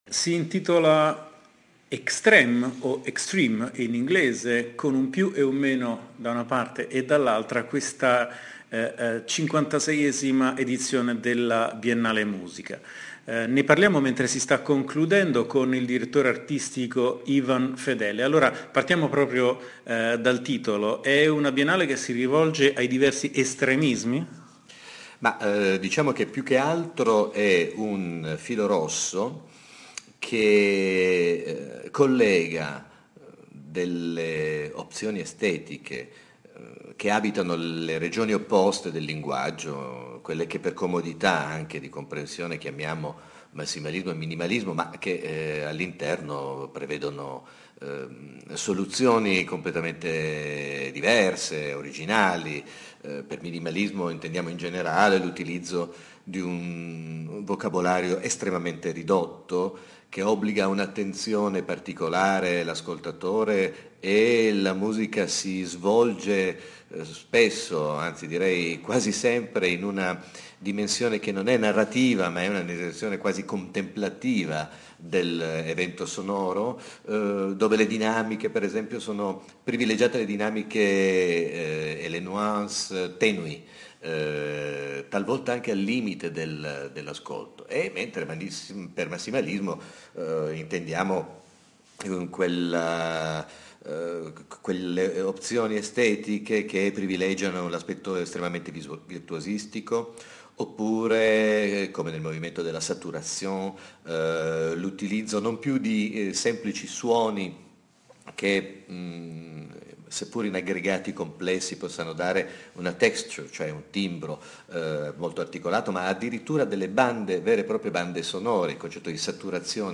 Nel link qui sotto l'integrale dell'intervista al direttore artistico Ivan Fedele